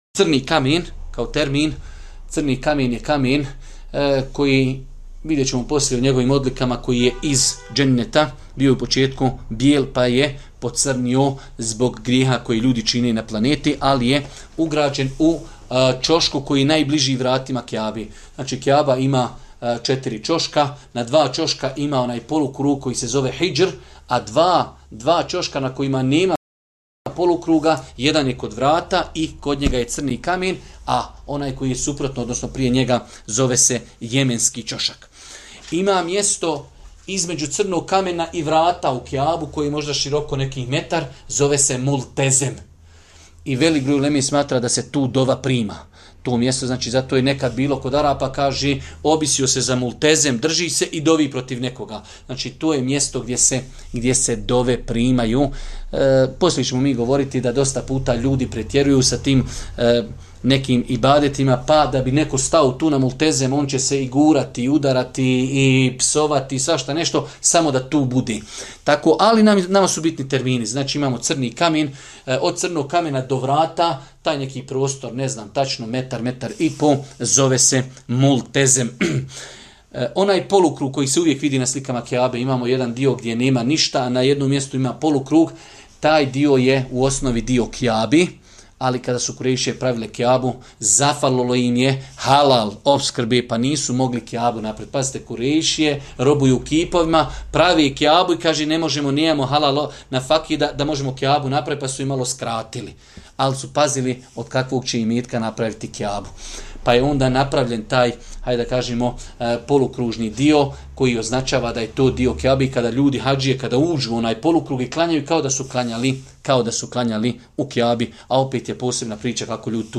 Odgovor prenosimo iz serijala predavanja o pravnim propisima hadža